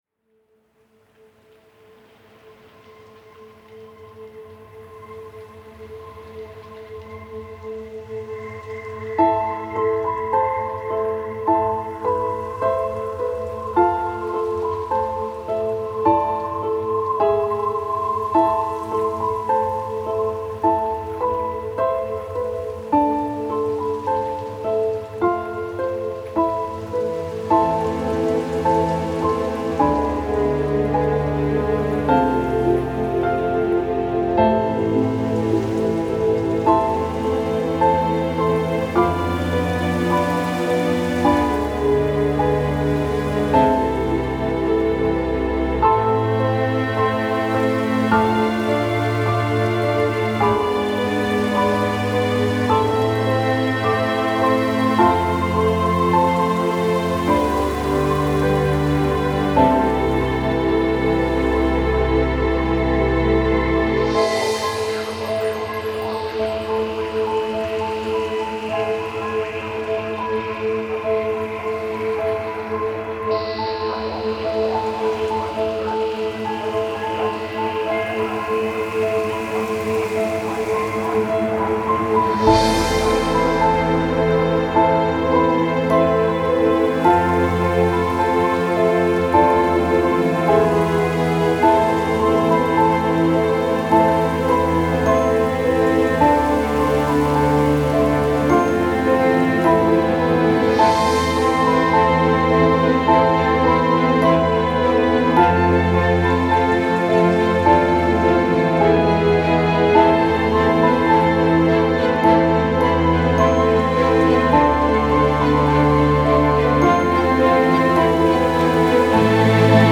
An emotional song featuring mainly piano, harp and strings. Logic factory sounds and plug-ins were used for the most part.